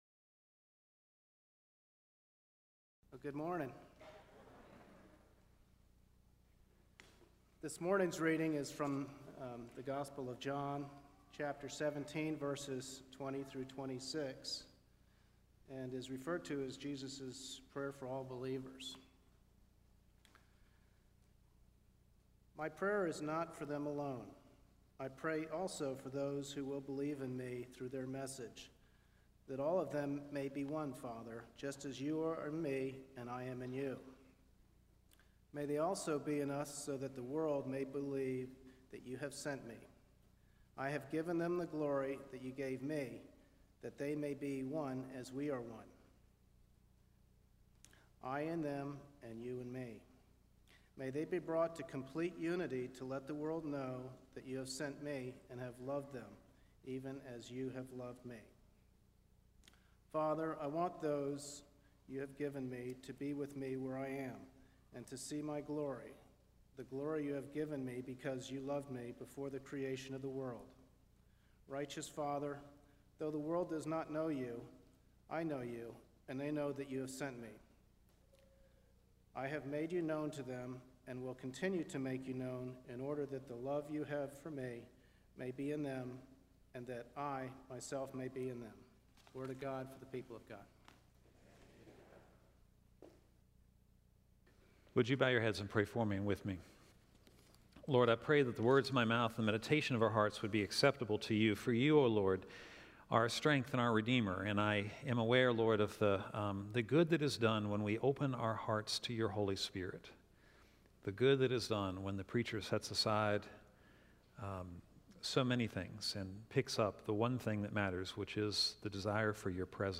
sermonaudio1-5-14.mp3